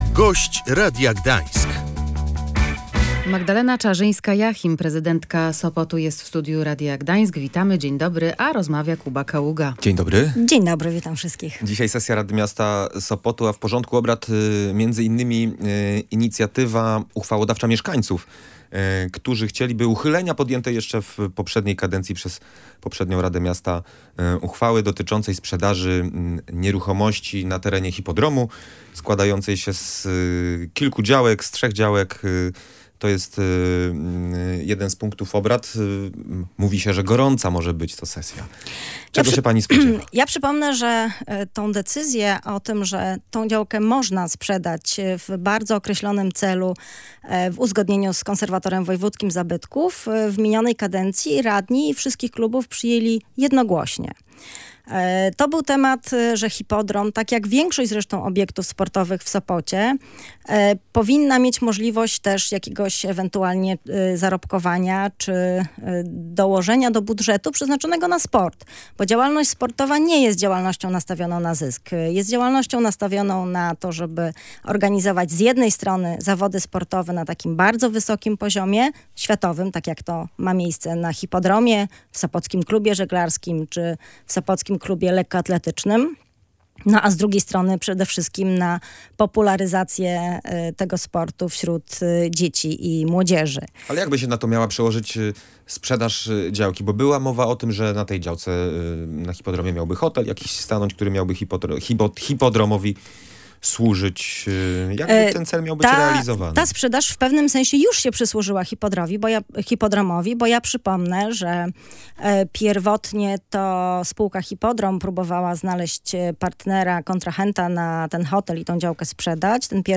Miasto nie uchyli uchwały umożliwiającej sprzedaż działki na hipodromie - mówiła w Radiu Gdańsk prezydent Sopotu Magdalena Czarzyńska-Jachim.